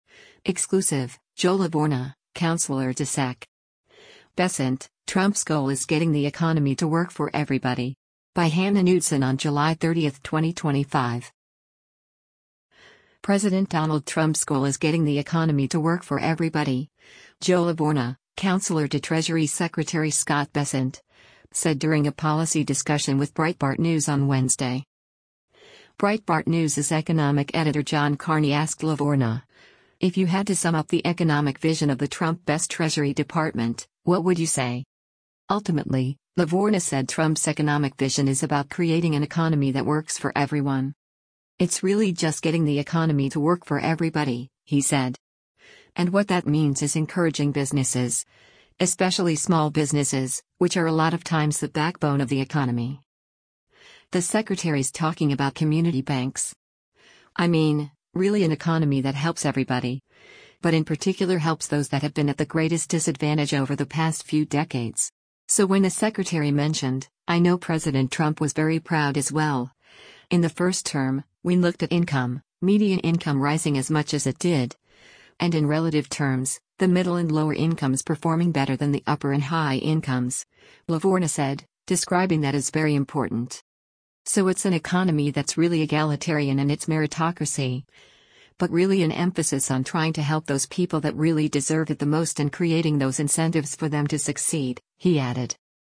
President Donald Trump’s goal is “getting the economy to work for everybody,” Joe Lavorgna, counselor to Treasury Secretary Scott Bessent, said during a policy discussion with Breitbart News on Wednesday.